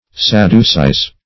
Search Result for " sadducize" : The Collaborative International Dictionary of English v.0.48: Sadducize \Sad"du*cize\, v. i. [imp.